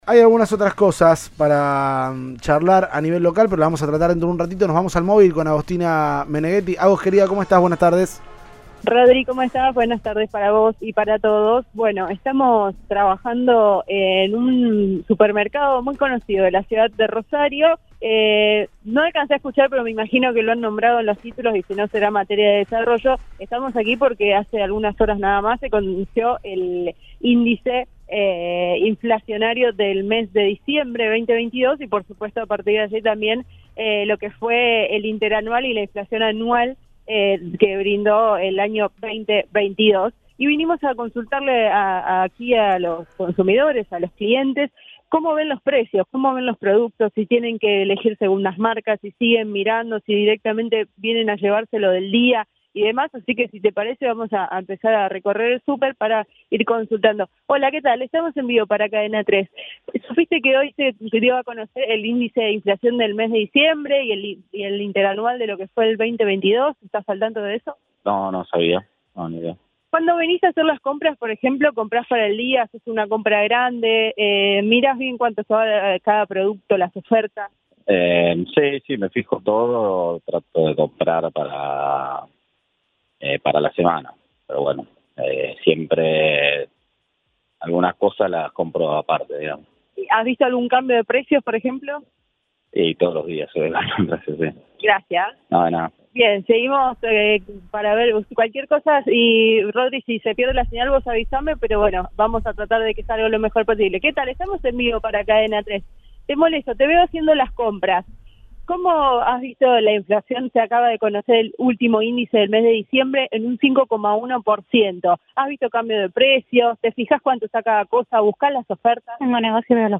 Audio. Consumidores rosarinos hablan sobre la inflación y los precios en supermercados.
El índice de precios al consumidor aumentó 5,1% en diciembre y acumuló una suba del 94,8% durante todo el 2022, informó este jueves el Instituto Nacional de Estadística y Censos (Indec) y el móvil de Cadena 3 Rosario salió a recorrer supermercados de la ciudad y dialogó con consumidores que para Informados al regreso dijeron esto: